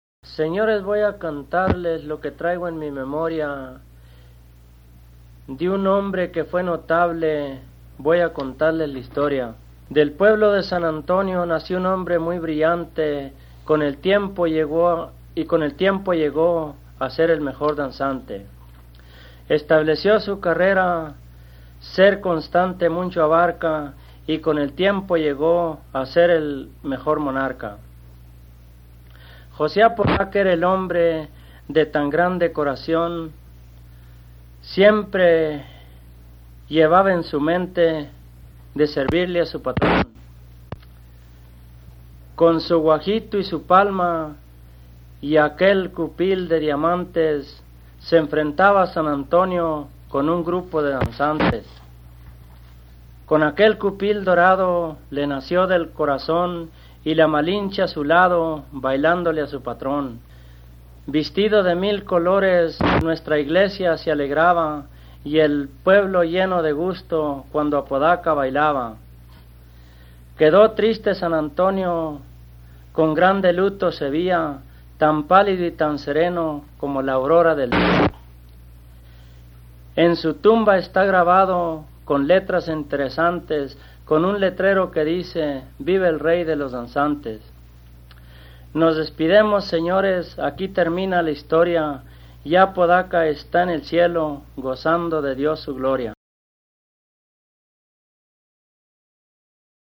Corrida